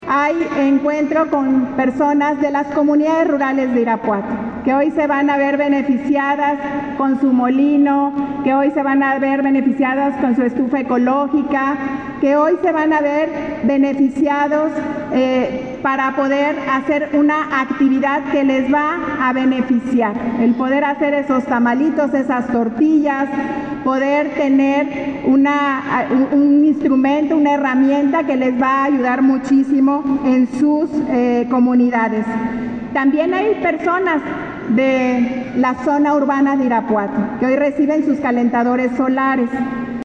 AudioBoletines
Lorena Alfaro, presidenta municipal